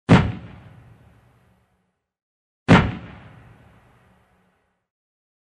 Звуки танков
8. Два выстрела один за другим